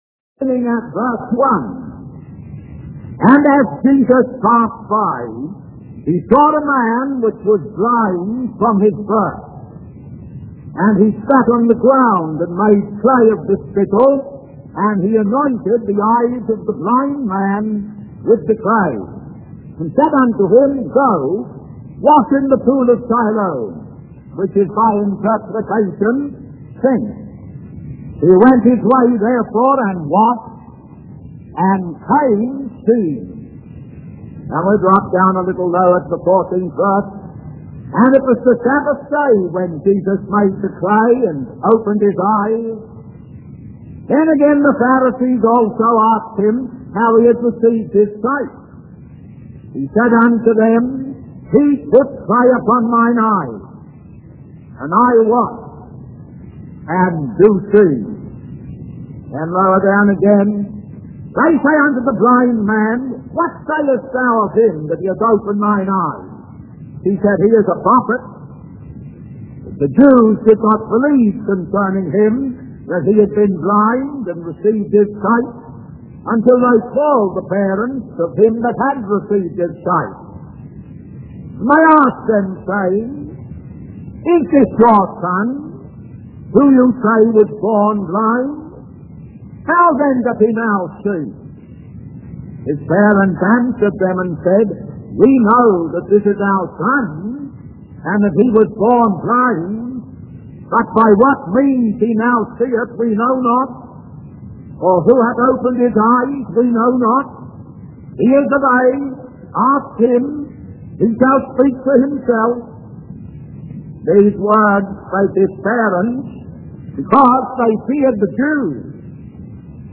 In this sermon, the preacher tells the story of a man who lived a worldly life, indulging in activities like gambling, dancing, and hunting.